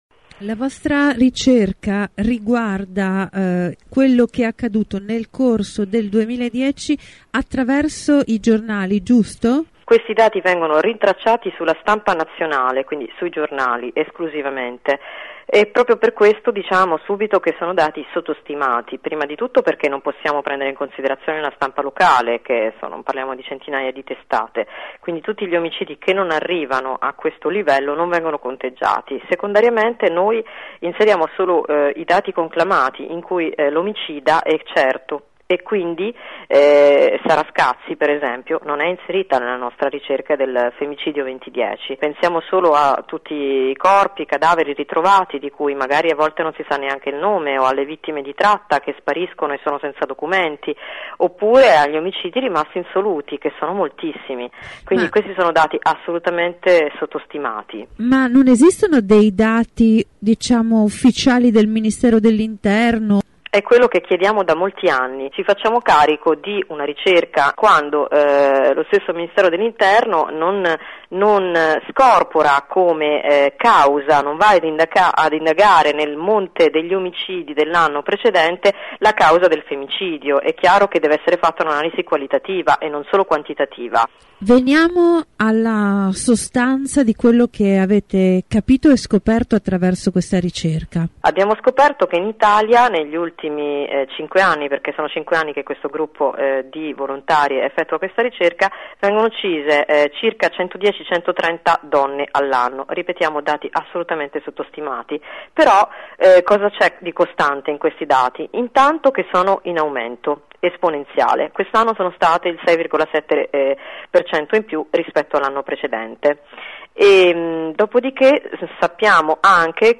presenta il report in questa intervista